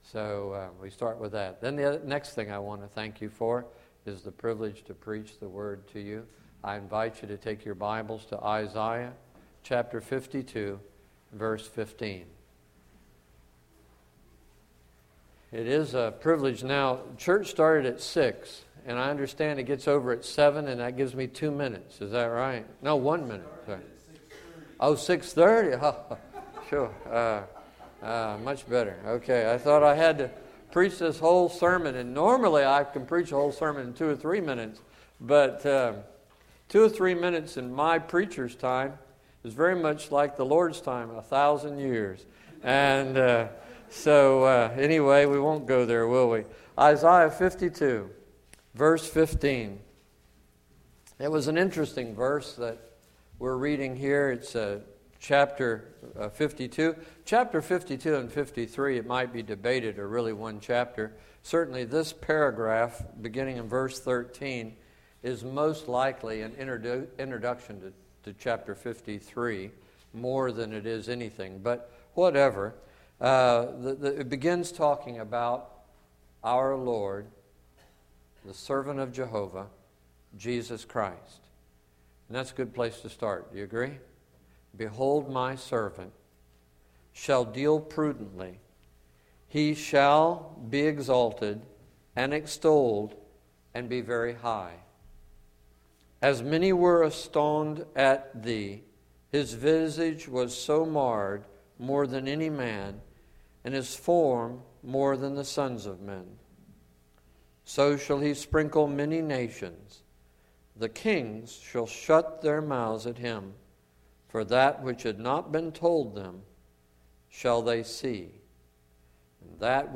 Sunday, October 30, 2011 – Evening Message
2011 Sermons guest speakers